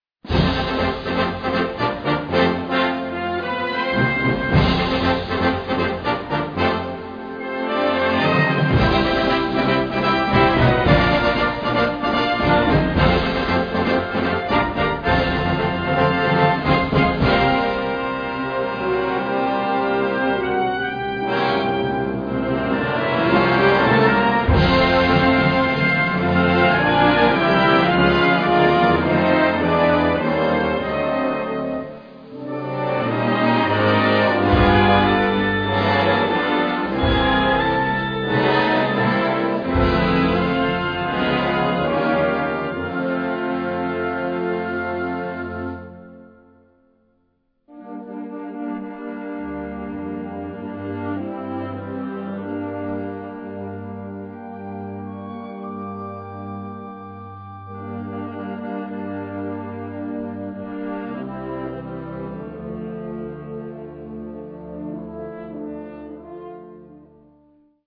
Dans l'ouverture, on entend une mélodie portoricaine.
orchestre d'harmonie